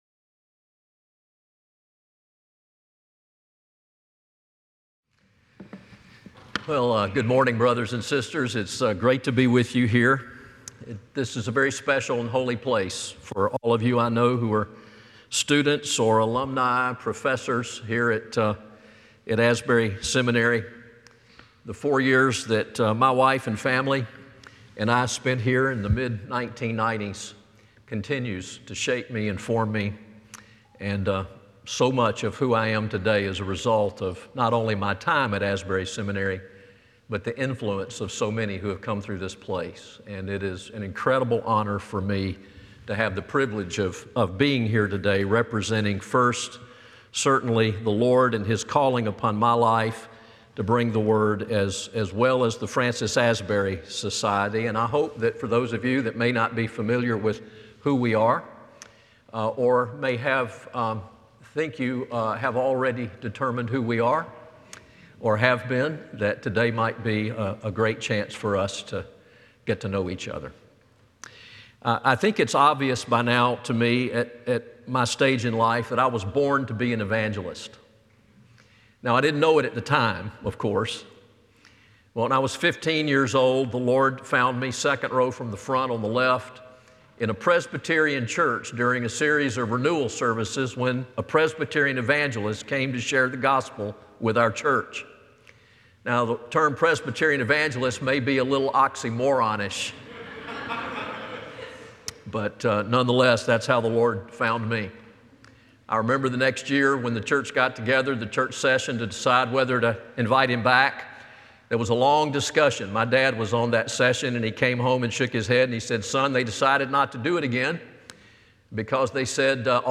The following service took place on Tuesday, October 22, 2024.